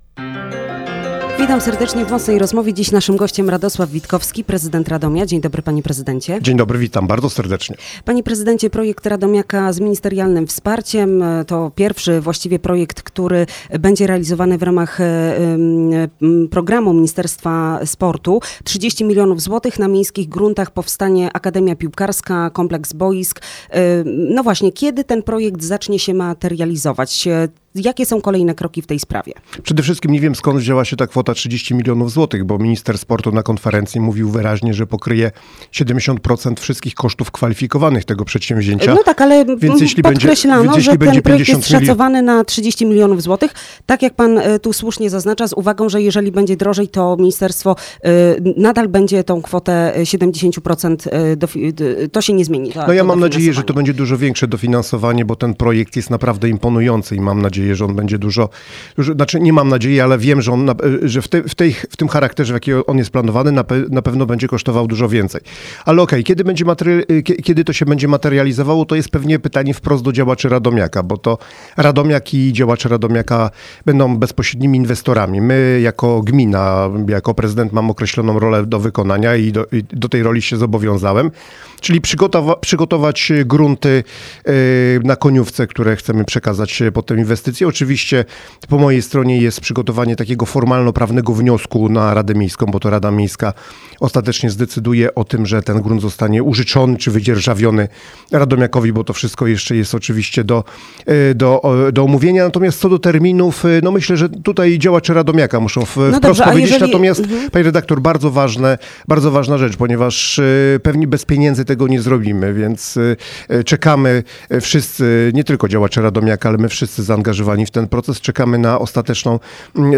Prezydent Radomia Radosław Witkowski był gościem